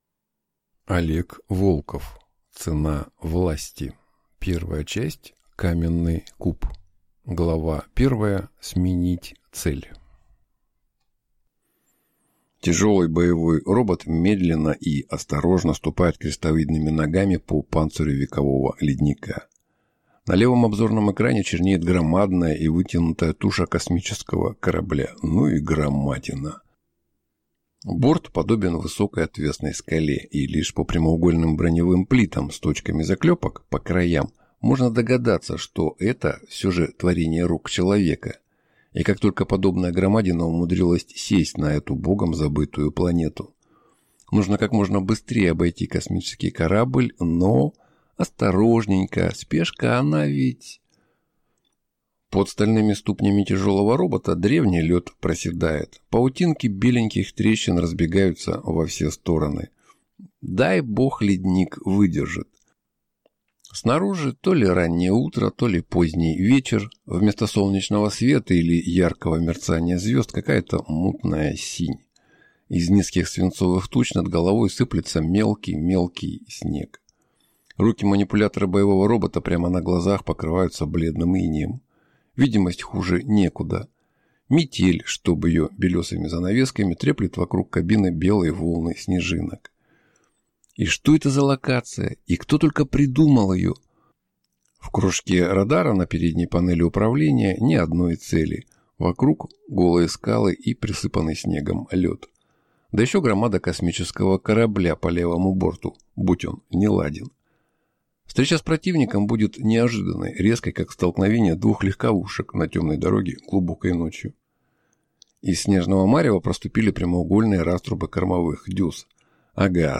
Аудиокнига «Рассудок маньяка».